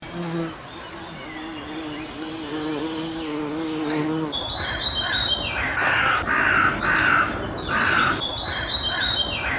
Waldgeräusch 4: Hummel, Waldvogel und Krähe / forest sound 4: bumblebee, forest bird and crow